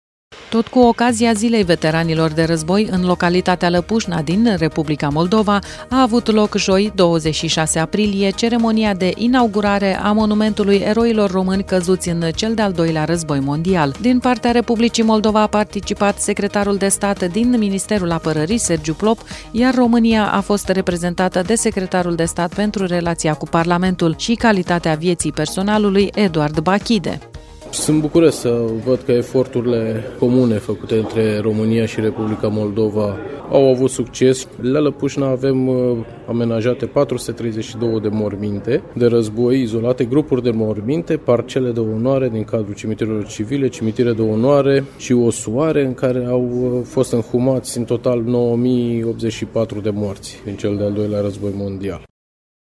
Tot cu ocazia Zilei Veteranilor de Război, în localitatea Lăpușna din Republica Moldova a avut loc joi, 26 aprilie, ceremonia de inaugurare a Monumentului Eroilor Români căzuți în cel de-al Doilea Război Mondial.